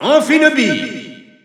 Announcer pronouncing Greninja in French.
Category:Greninja (SSBU) Category:Announcer calls (SSBU) You cannot overwrite this file.
Greninja_French_Announcer_SSBU.wav